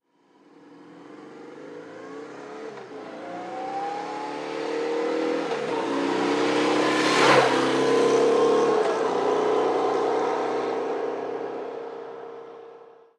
Moto marca BMW pasando a velocidad normal 1
motocicleta
Sonidos: Transportes